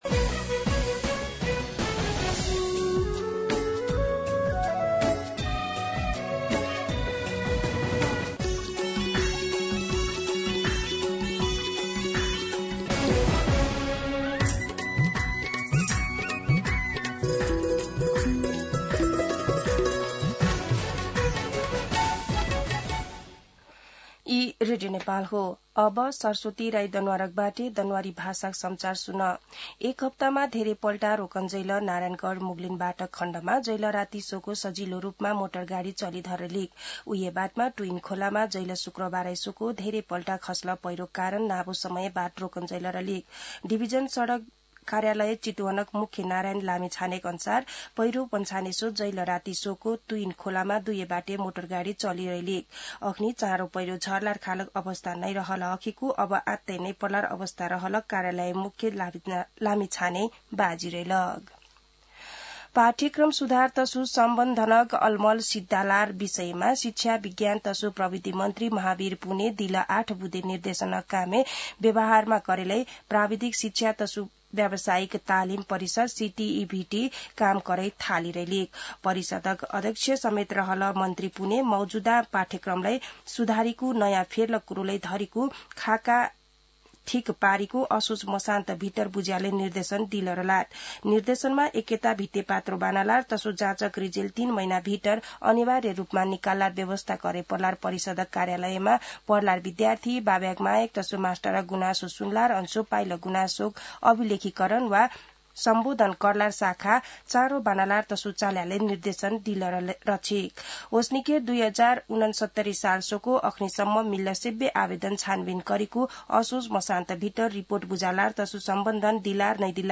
दनुवार भाषामा समाचार : १० असोज , २०८२